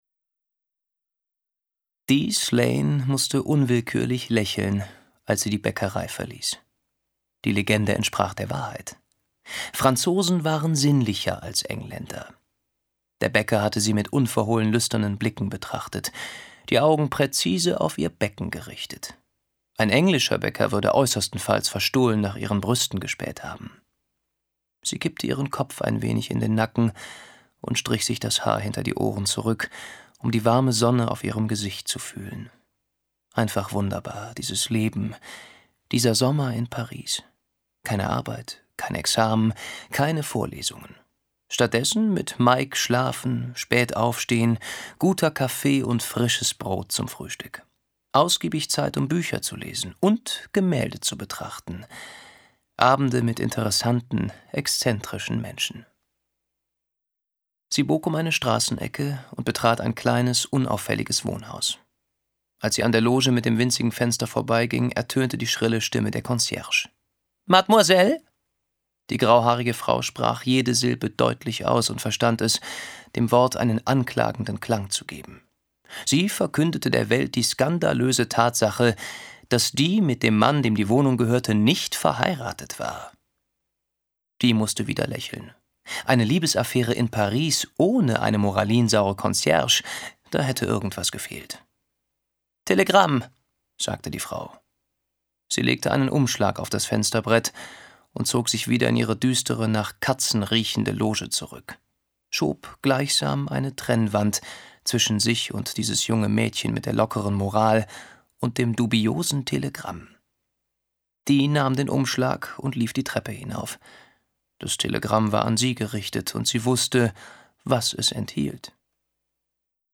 Jahrhundert • Auktion • Belletristik und verwandte Gebiete • Beltracchi • Beltracchi Die Kunst der Fälschung • Beltracchi Selbstportrait • Beltracchi Selbstporträit • Dedektiv • Der Fall Beltracchi • Der Fälscher • Der Modigliani Skandal • Der Modigliani-Skandal • detective • Detektiv • Deutsche Krimis • Die Kunst der Fälschung • Die Kunst des Fälschens • England • England / Großbritannien • Ermittler • Ermittlung • Ermittlungen • Europa • Falsche Bilder - echtes Geld • Falsche Bilder Echtes Geld • Fälschung • Follett Modigliani • Frankreich • Geheimdienst • Gerhard Richter • Gerhardt Richter • Helene Beltracchi • Hörbuch; Krimis/Thriller-Lesung • Ken Folett • Ken Follet • Kenn Follett • Komissar • Kommisar • Kommissar • Krimi • Krimi Bestseller • Kriminalpolizei • Kriminalroman • Kriminalromane • Kriminalromane & Mystery • Kriminalromane & Mystery • Krimis • Krimiserie • Krimis und Thriller • Kunst • Kunstauktion • Kunstfälscher • Kunstfälschung • Kunstkrimi • Kunstskandal • Kunstthriller • Landhauskrimi • Livorno • London • Meisterfälscher • Moderne Kunst • Modigliani • Modigliani Skandal • Modigliani-Skandal • Modiliani • Mord • Mörder • Mystery • Paris • Polizei • Polizist • Polizistin • Privatdetektiv • Psychothriller • Regionalkrimi • Rekordpreis • Schlitzer • Sechzigerjahre • Sechziger Jahre • Serienkiller • Serienkrimi • Serienmord • Serienmörder • Spannung • Spannungsroman • Stefan Koldehoff • Tatort • Thriller • Thriller / Spannung • Tobias Timm • Toskana • Verbrechen • Verfolgt • Wolfgang Beltracchi